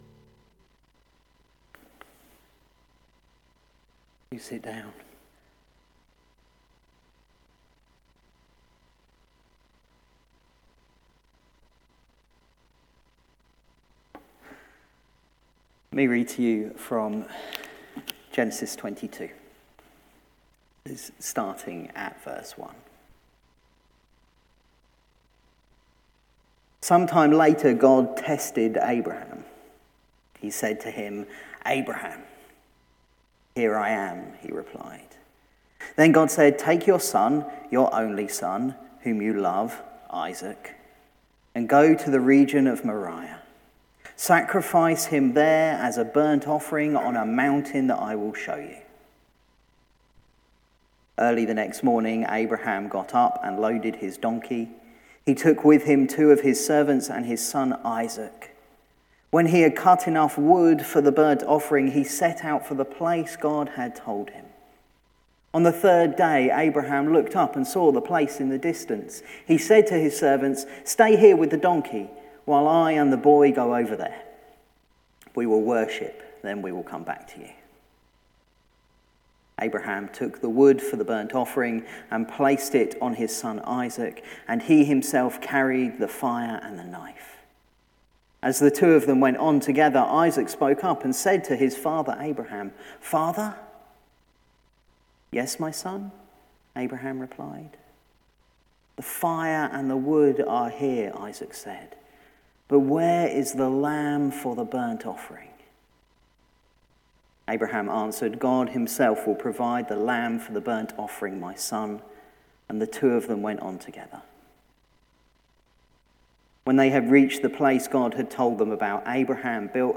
Genesis 22: 1-12. Service recorded on 22nd January 2023, released on 5th February in lieu of All Age Service. Tagged with Morning Service Audio (MP3) 23 MB Previous Small Group Sunday Next The Door